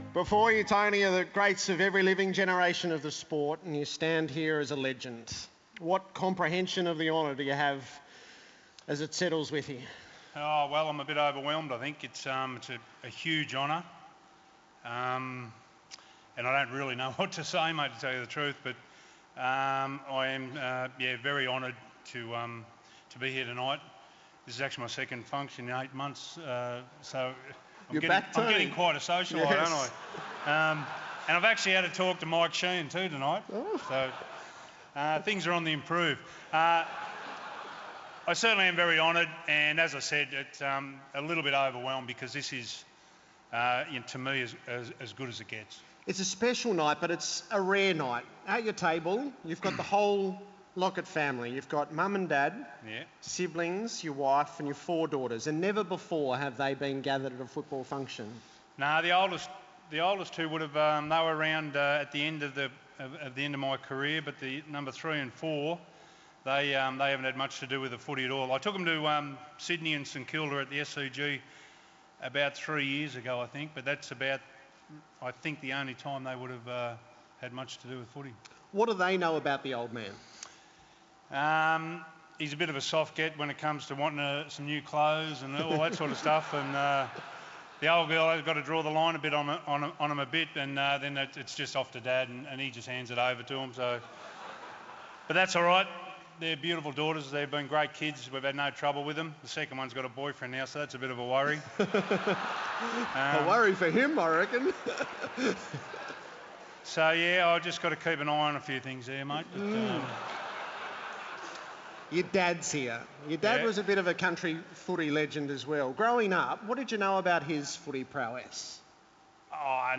Tony Locket Legend Induction speech